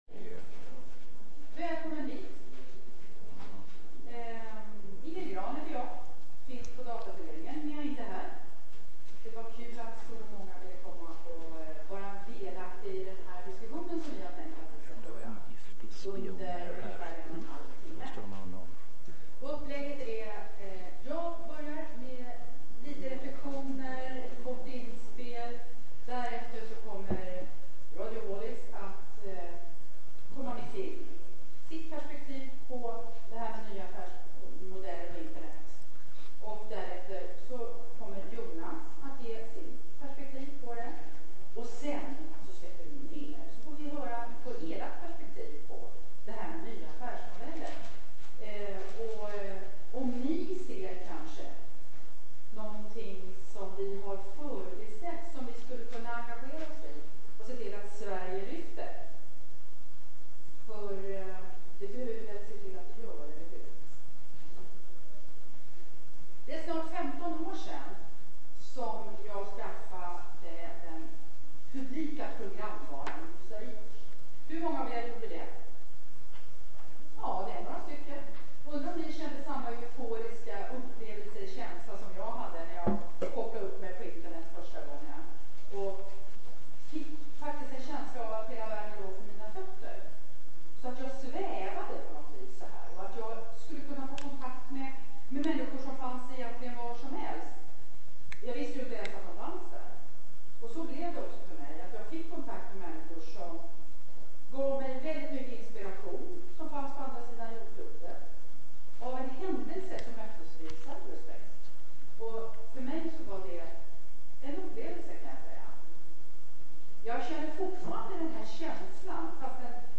Under v�rt seminarium kommer vi att belysa hindren och m�jligheterna fr�n olika perspektiv.